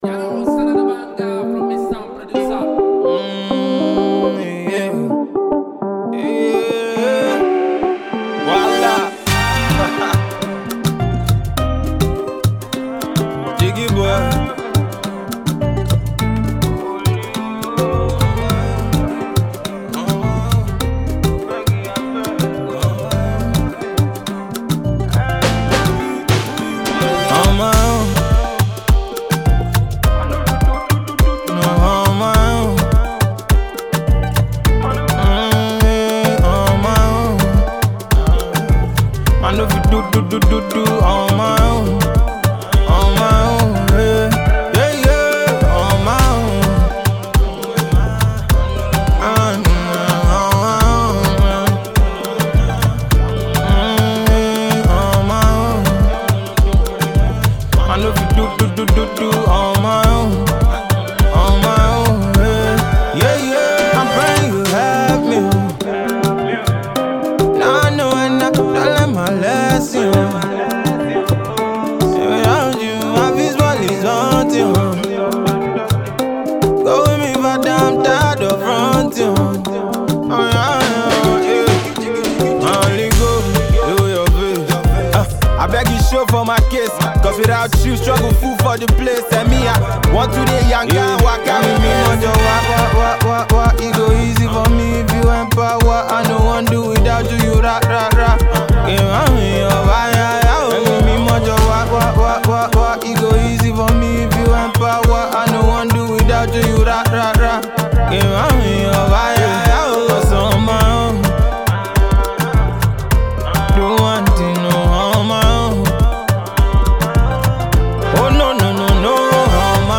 Gospel
Sensational AfroGospel artist
make you tap your feet & bounce your head to the rhythm